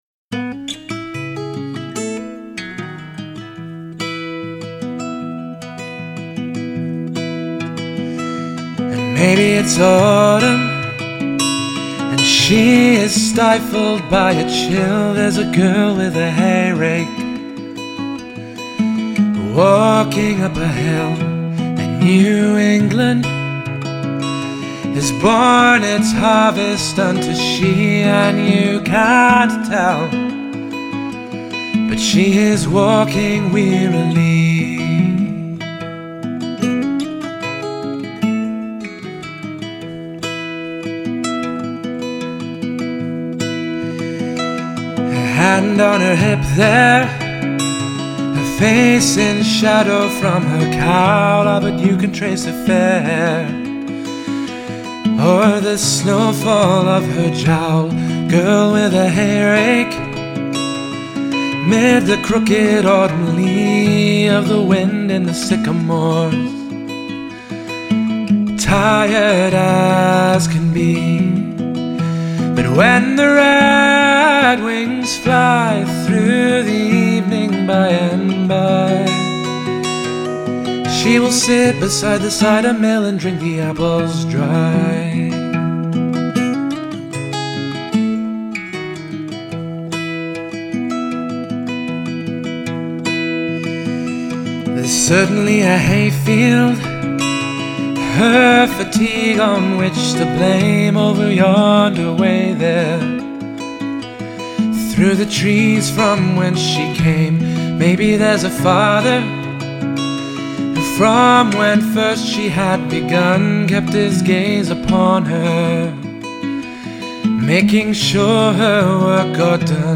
guitar, vocal